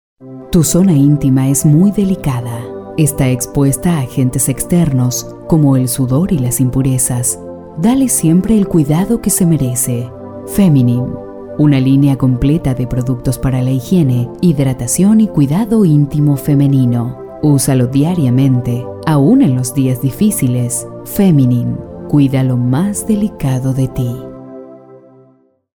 SPOT PUBLICITARIO
Producimos spot en crudo o productos terminados con pista musical y efectos requeridos
FEMENINE/Locutor